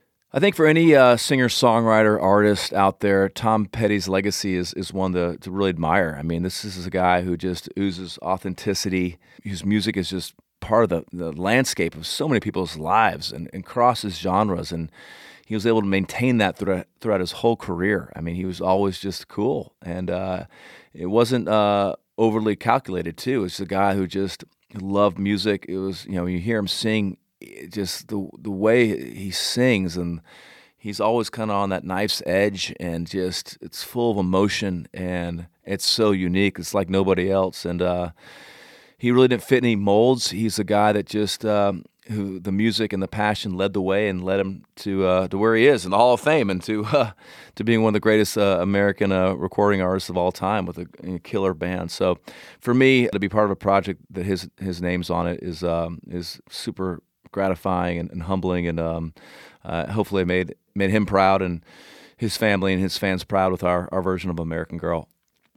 Dierks Bentley talks about the legacy of Tom Petty and his music.